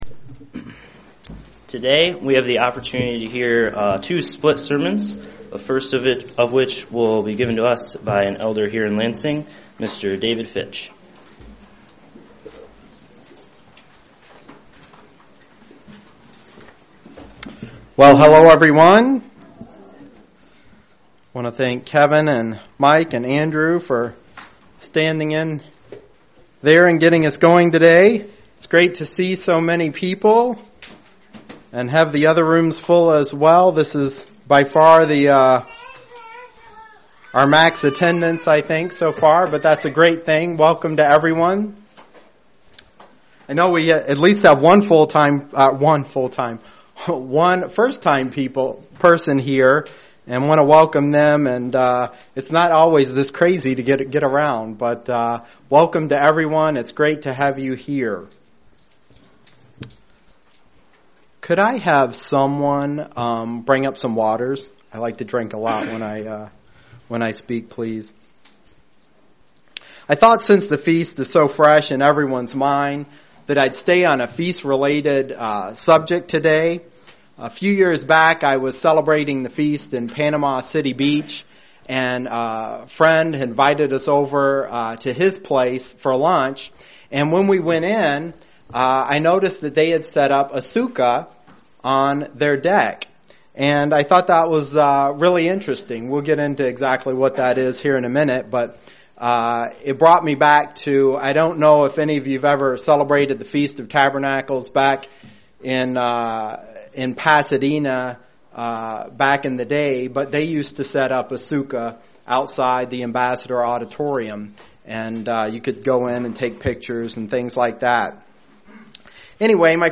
UCG Sermon